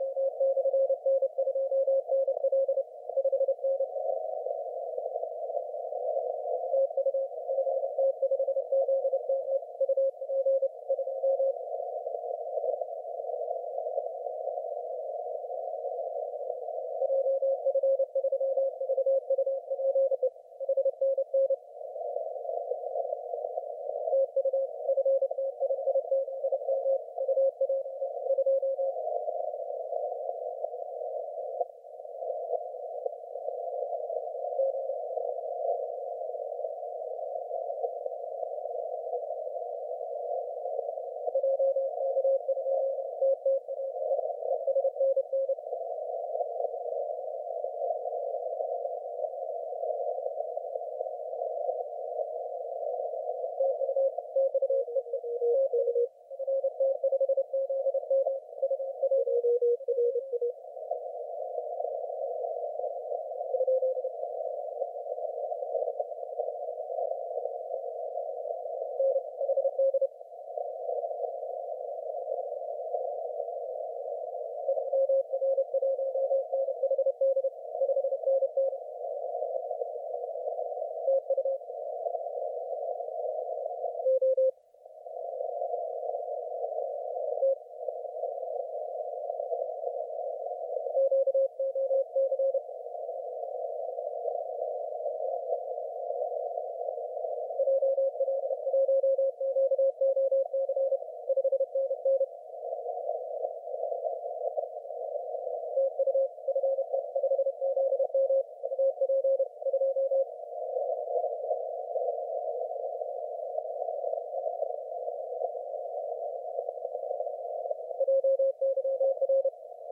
FT5ZM 20M CW SP